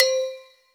AFFRBELLC4-L.wav